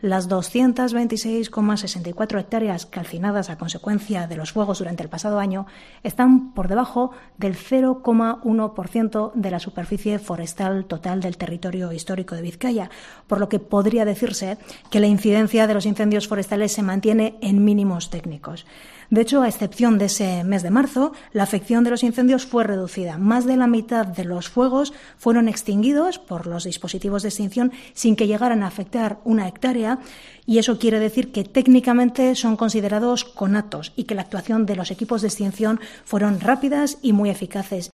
Elena Unzueta, portavoz foral